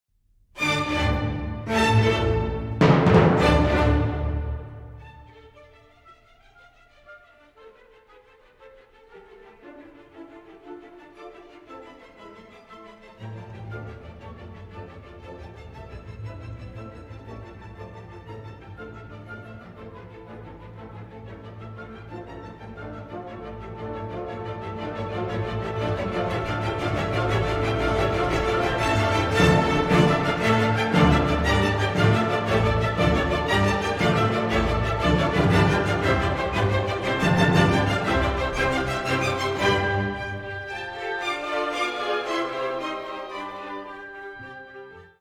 To me, this passage depicts the busyness of methodical and analytical mental processes at work.
By the way, the snippets of audio we’ve been listening to are from that 1972 recording of the Chicago Symphony Orchestra and Chorus, Georg Solti conducting, recorded at the Krannert Center of Performing Arts on the campus of the University of Illinois.